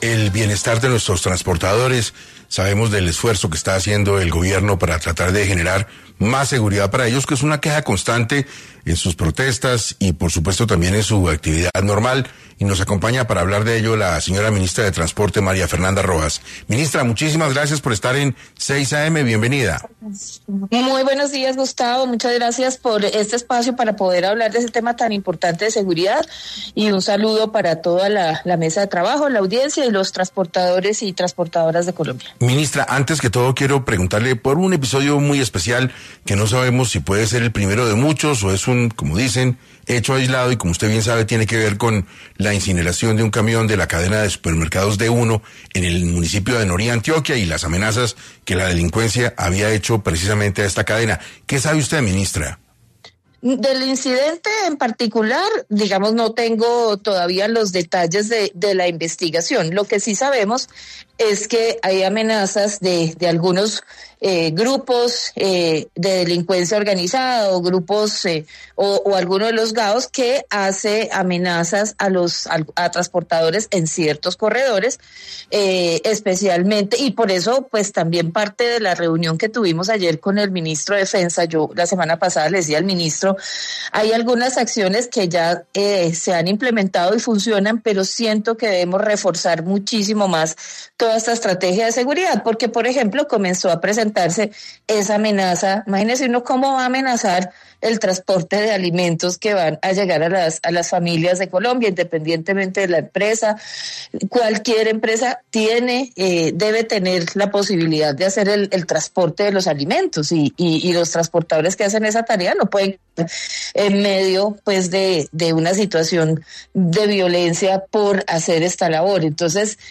En Caracol Radio estuvo la ministra de Transporte, María Fernanda Rojas